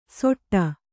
♪ soṭṭa